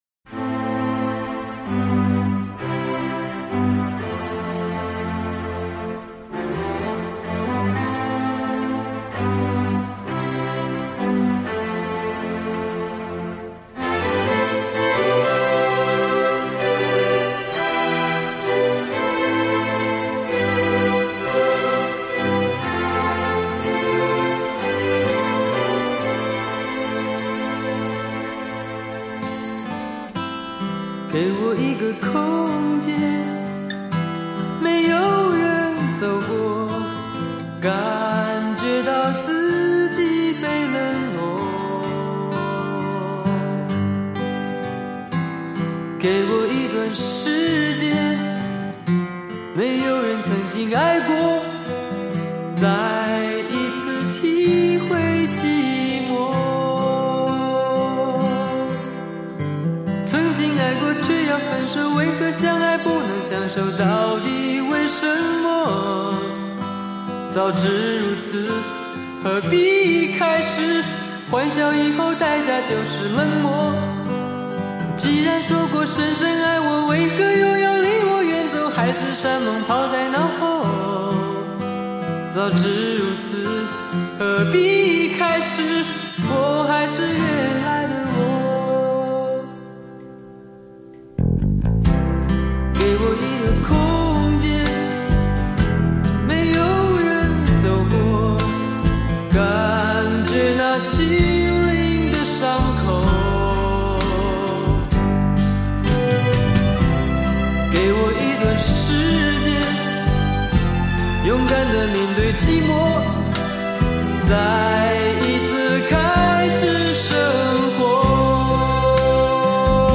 乐坛一直最风光天王级男歌手